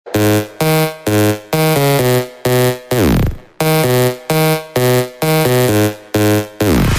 • Качество: 128, Stereo
громкие
электронная музыка
без слов